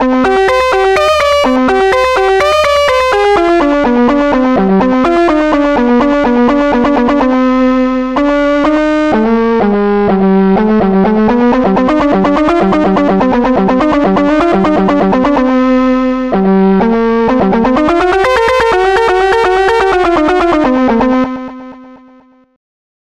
Protracker Module
2 channels
guitar-solo